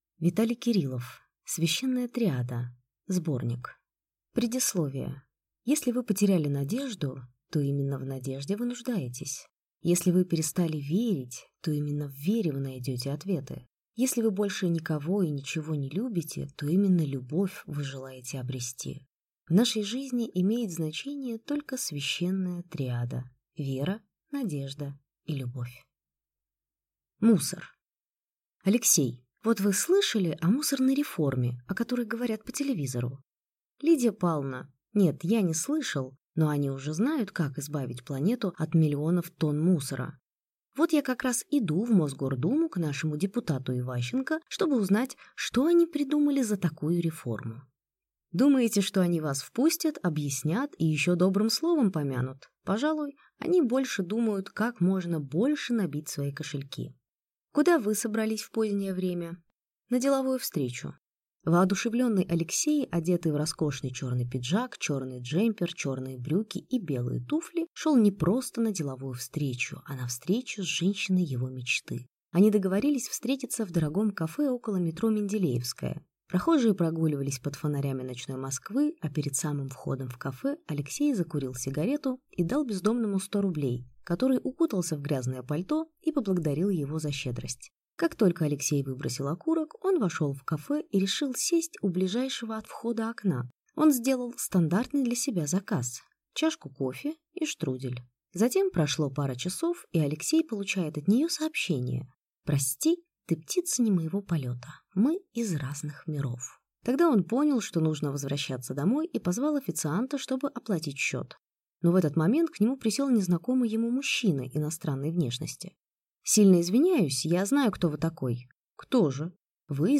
Аудиокнига Священная триада. Сборник | Библиотека аудиокниг